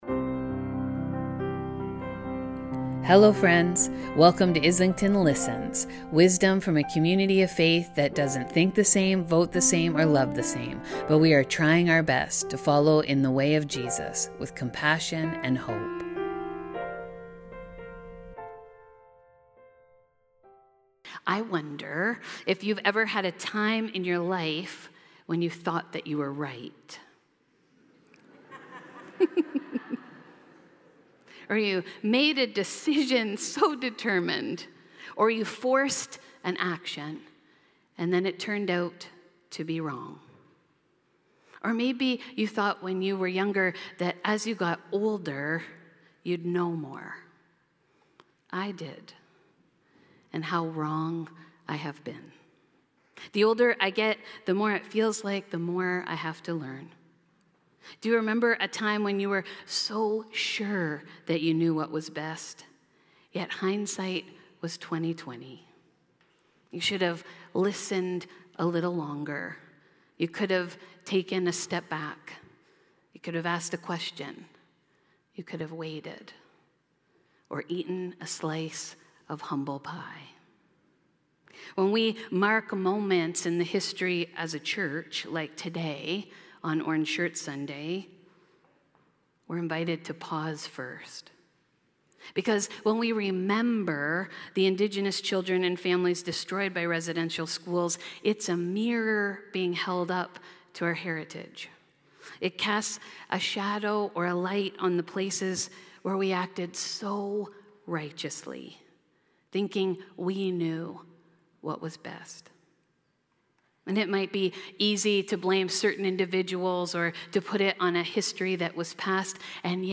In this week’s sermon were called to hospitality and making room for others.